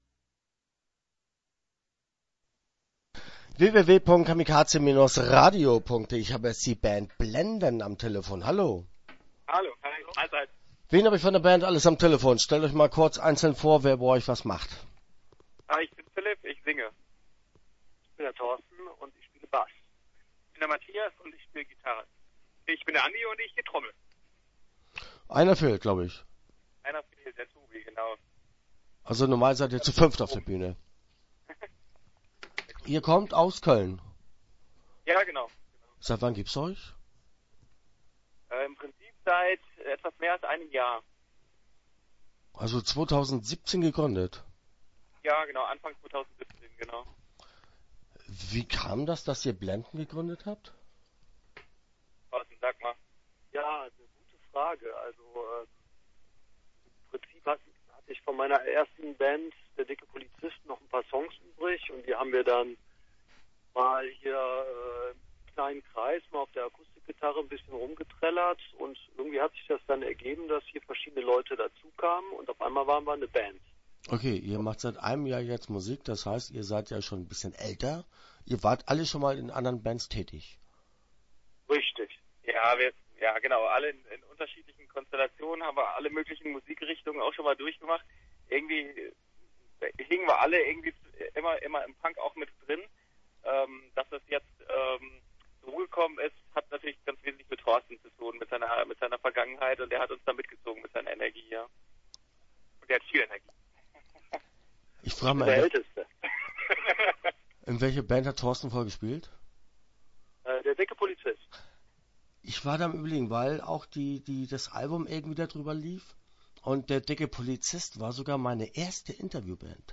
Start » Interviews » BLENDEN